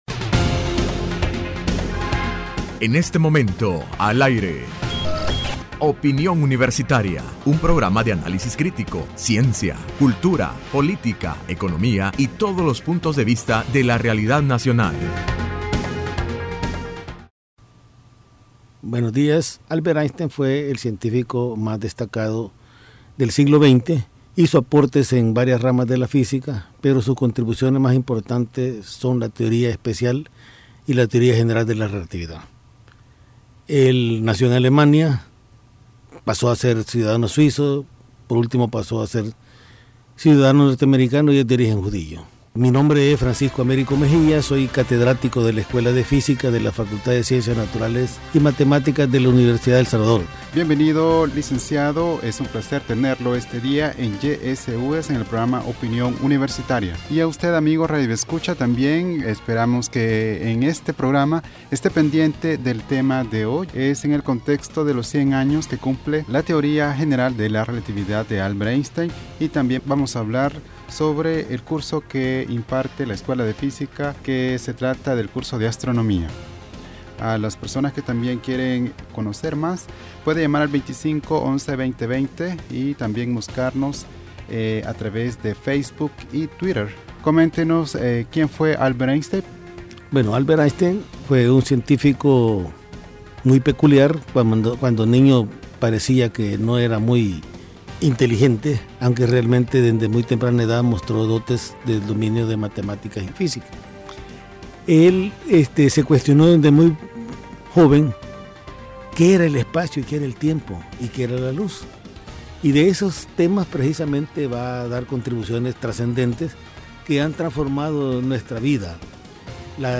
Entrevista Opinión Universitaria (01 octubre 2015): En el marco de los 100 años de la teoría de la relatividad de Albert Einstein se desarrolla el curso libre de astronomía de la Escuela de Física de la UES